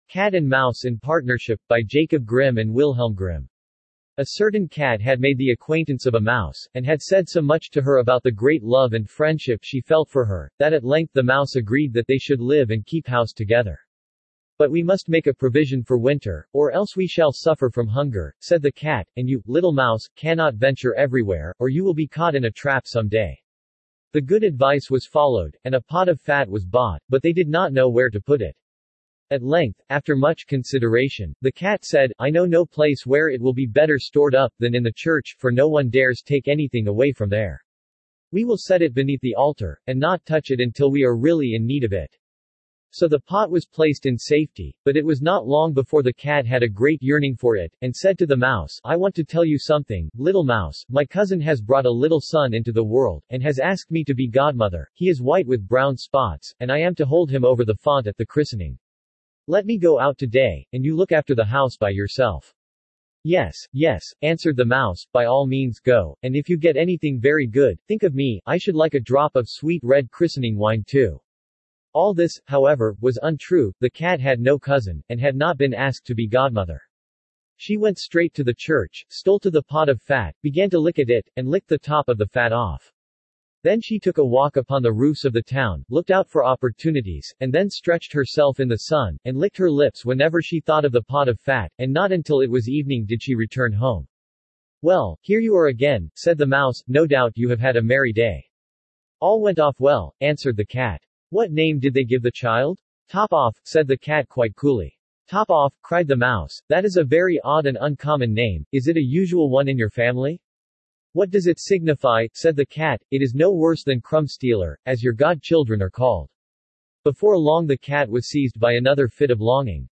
Standard (Male)
cat-and-mouse-in-partnership-en-US-Standard-D-968cfbcd.mp3